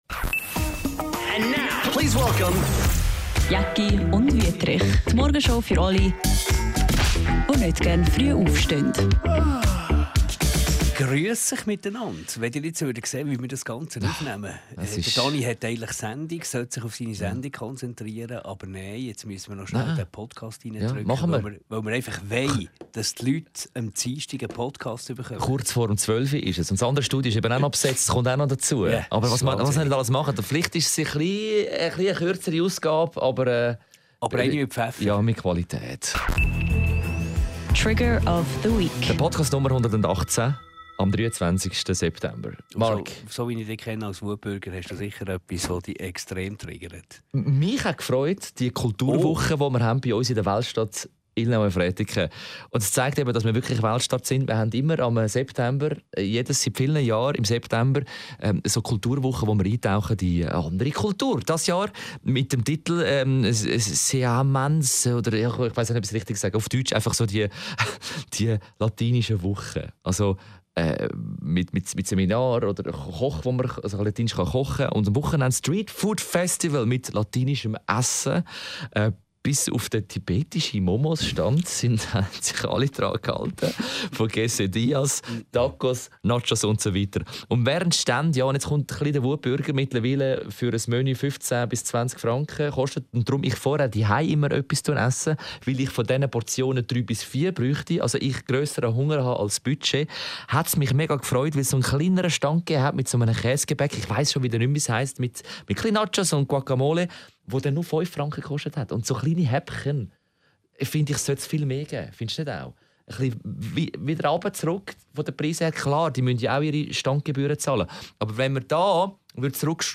Diese Sendung ist kurz. Weil, sie wurde von zwei überrabeiteten Menschen noch kurz zwischen zwei Termine gezwängt und gedrängt.